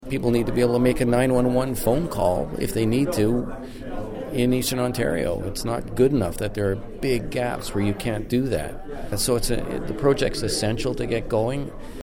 In an interview following the meeting